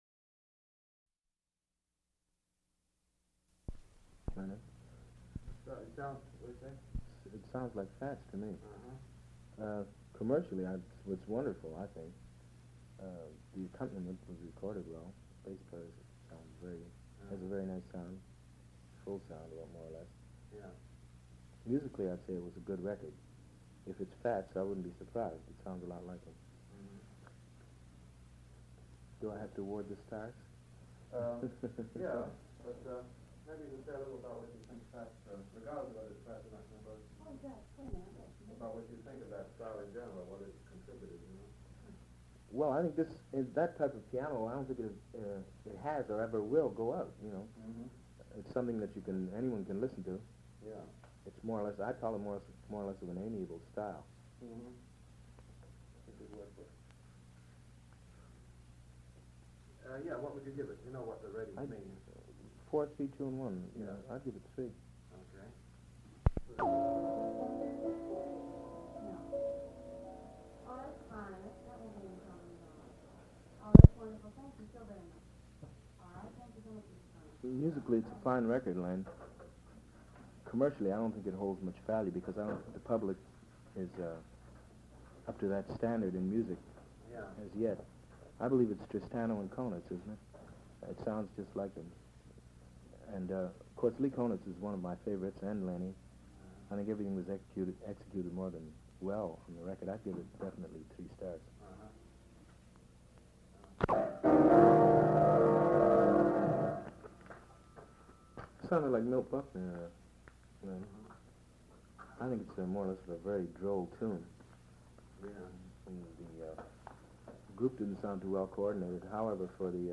Recording identified as most likely Oscar Peterson participating in one of Leonard Feather's blindfold tests. Oscar Peterson was a Canadian jazz pianist.